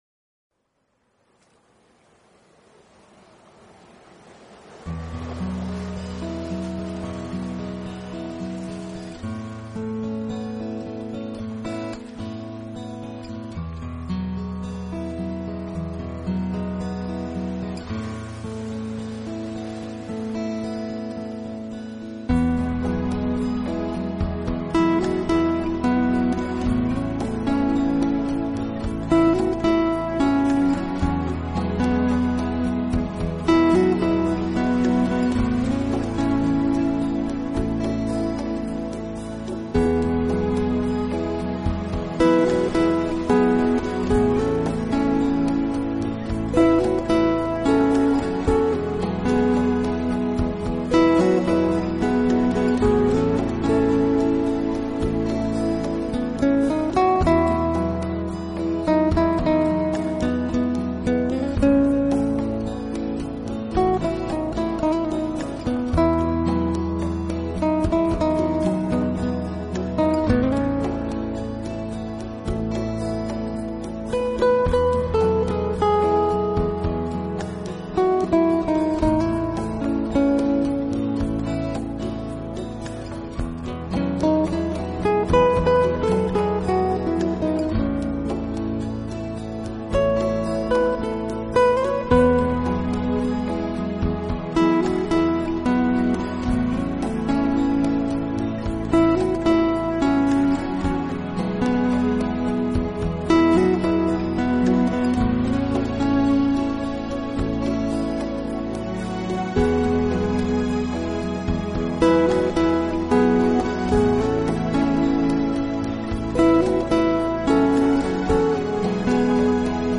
Genre..........: New Age
QUALiTY........: MP3 44.1kHz / Stereo